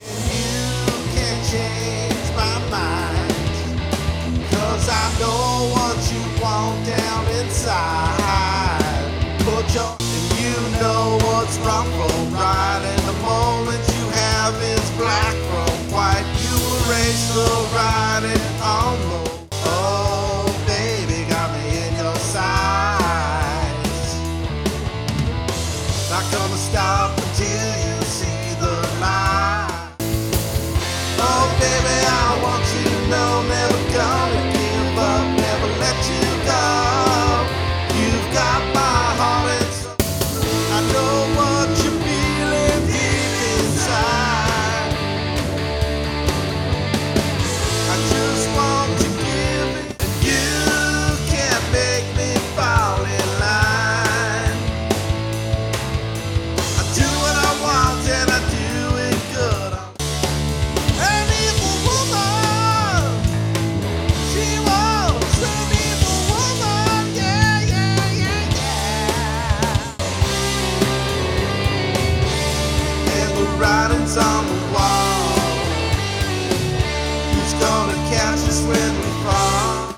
classic rock sound
Guitars
Drums
Vocals, Bass